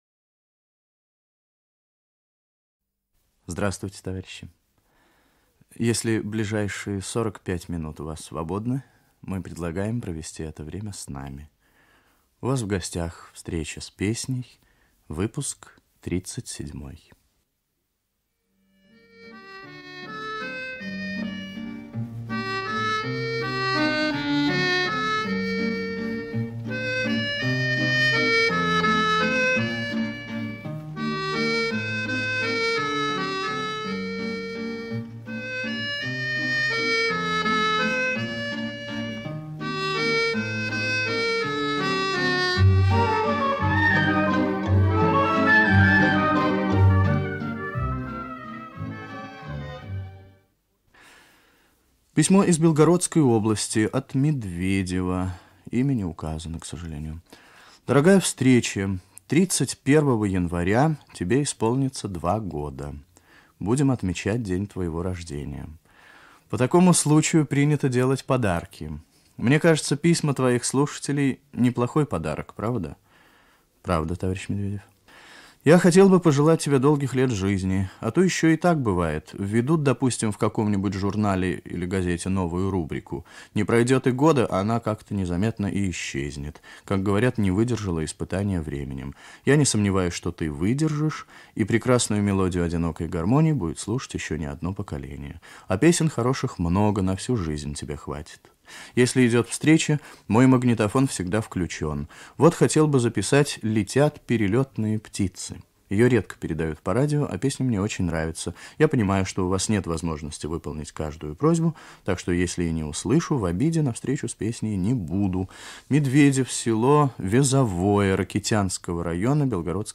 Встреча с песней. Выпуск 37 Ведет передачу Виктор Татарский .